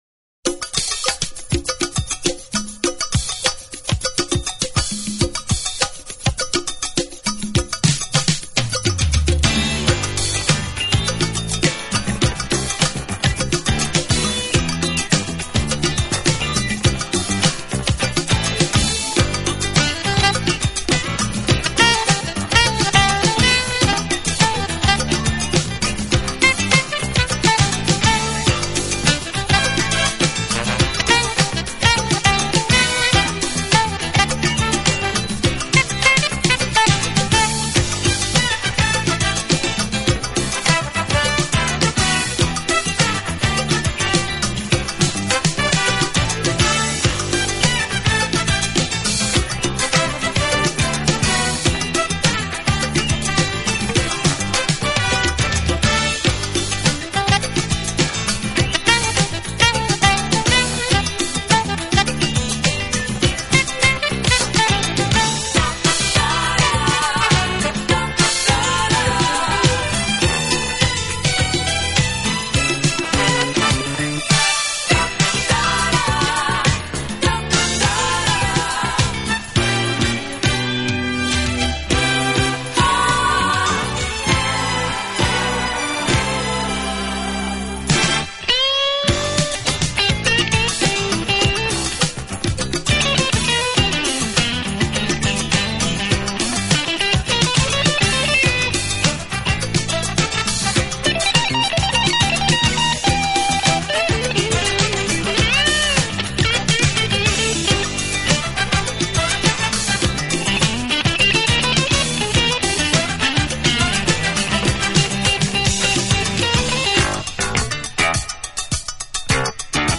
其风格清新明朗，华丽纯朴，从不过分夸张。